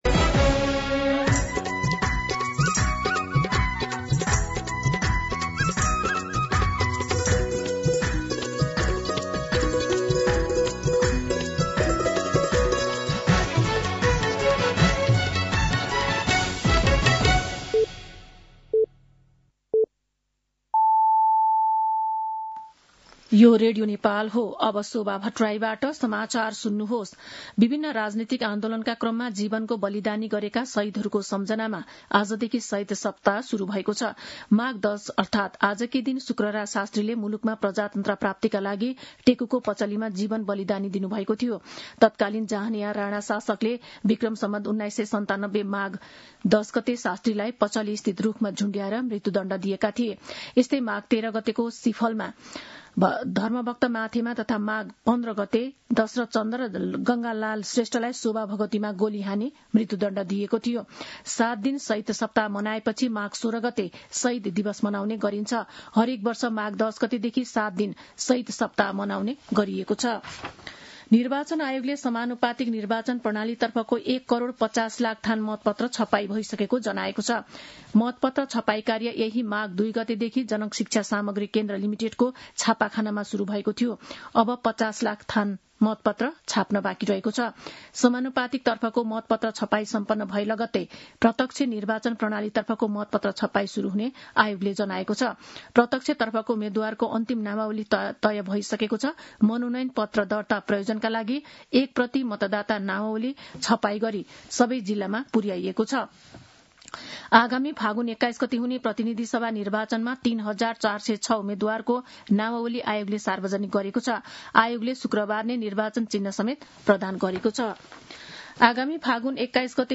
मध्यान्ह १२ बजेको नेपाली समाचार : १० माघ , २०८२